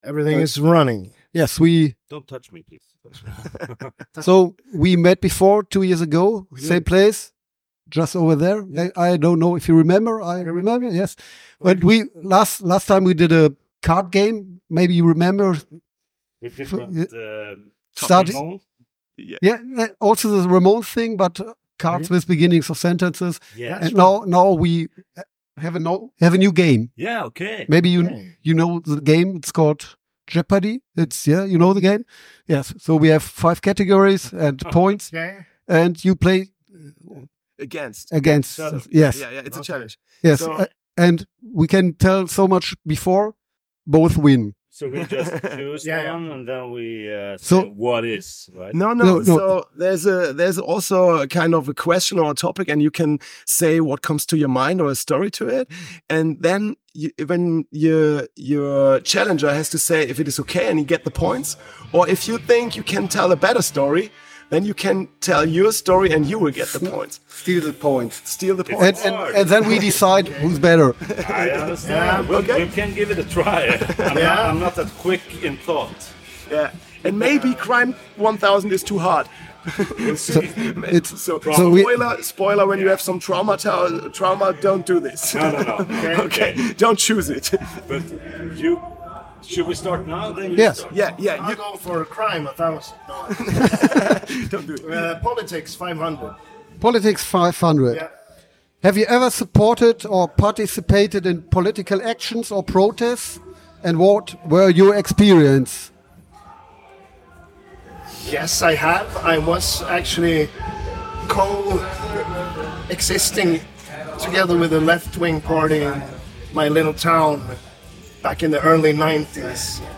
interview-punk-rock-holiday-24-no-fun-at-all.mp3